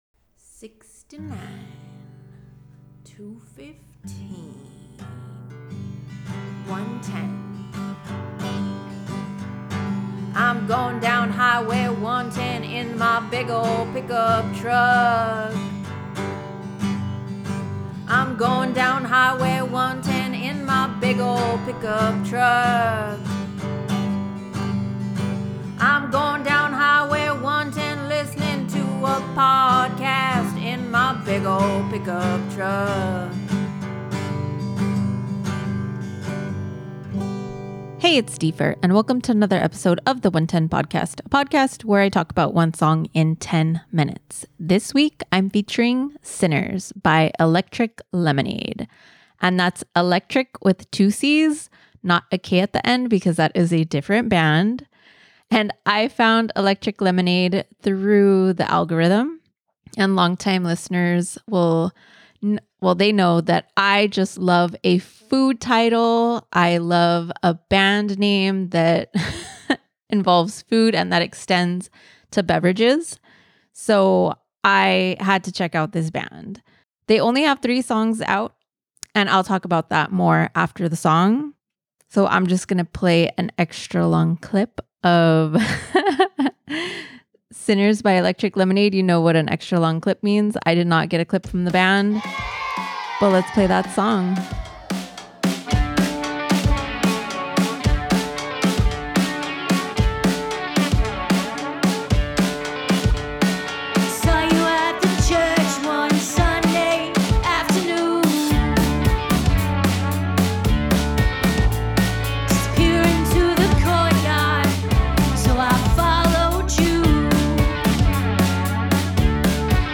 an energetic sing-a-long